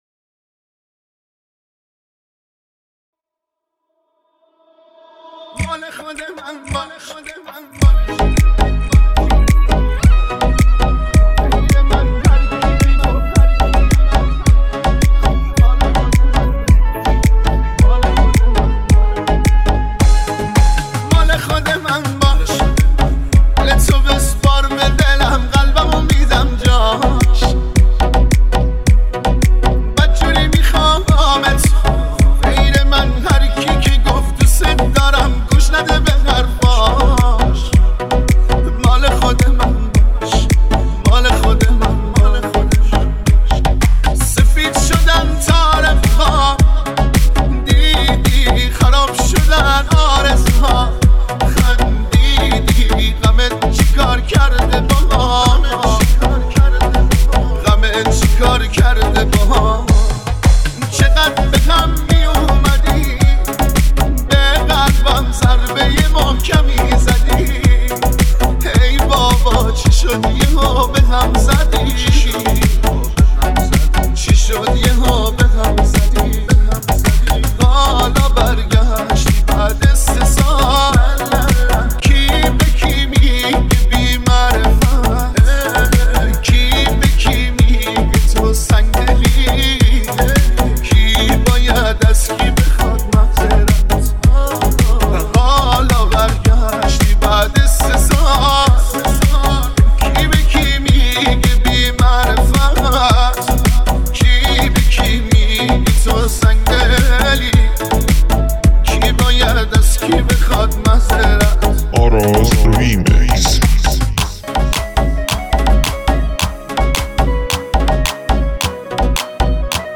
ریمیکس اصلی موزیک